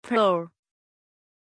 Pronunciation of Pearl
pronunciation-pearl-zh.mp3